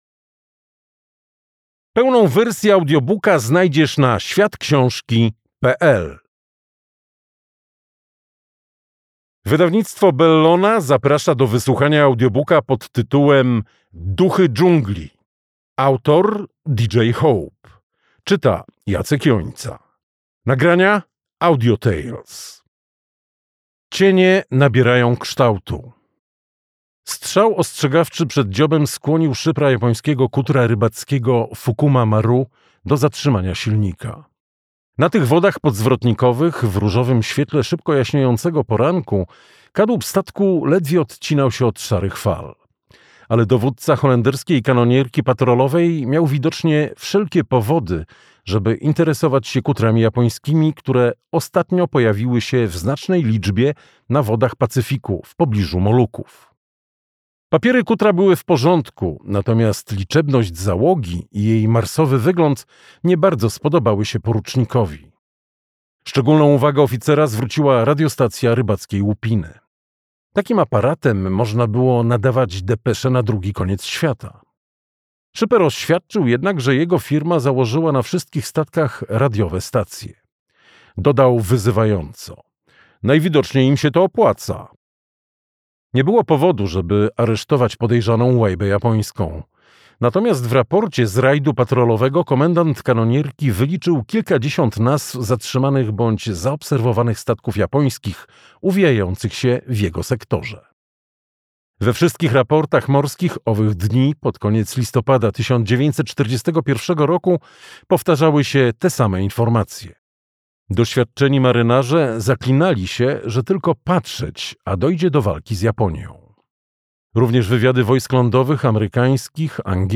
Duchy dżungli - D.J. Hope - audiobook